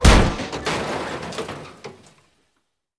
DrumBarrelHit.wav